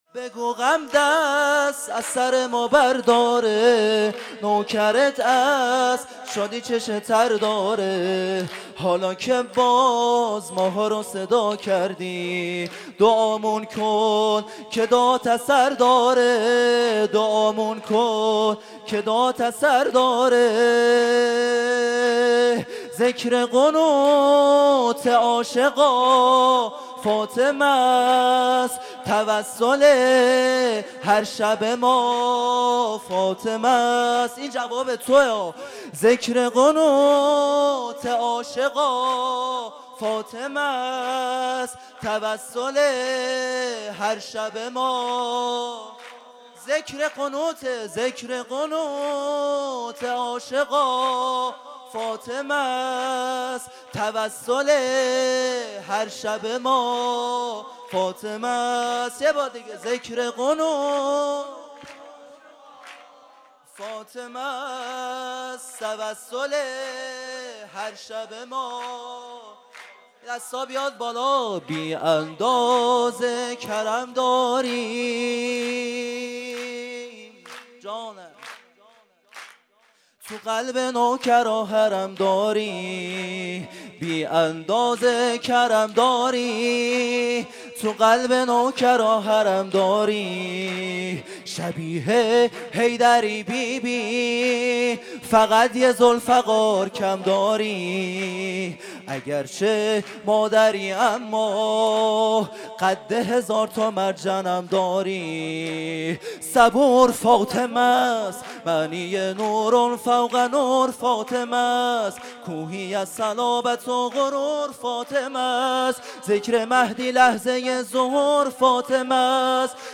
خیمه گاه - هیئت بچه های فاطمه (س) - سرود | ذکر قنوت
مراسم جشن ولادت حضرت فاطمه الزهرا (س)